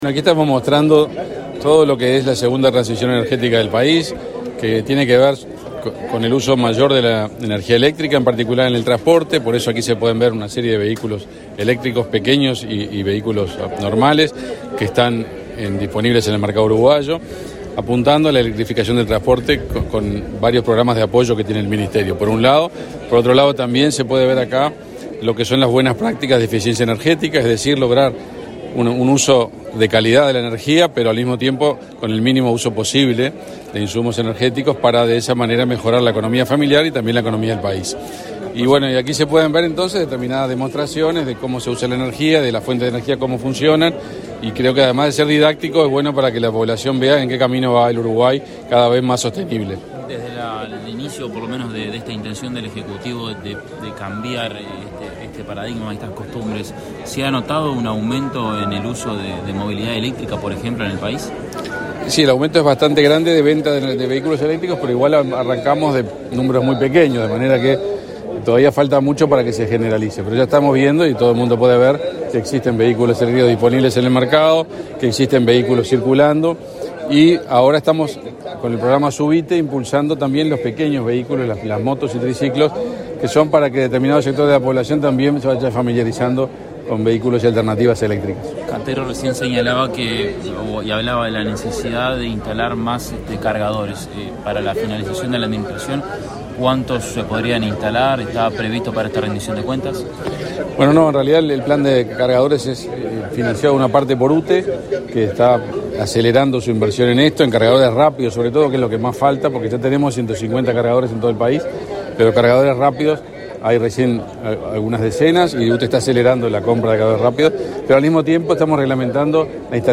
Declaraciones del ministro de Industria, Omar Paganini
El ministro de Industria, Omar Paganini, inauguró el stand de esa cartera en la Expo Prado y luego dialogó con la prensa.